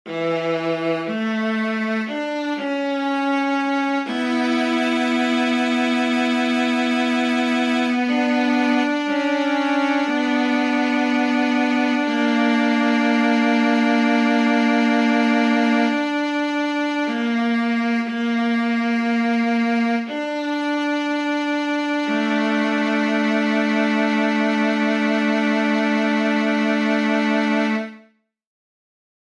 How many parts: 4
Type: Barbershop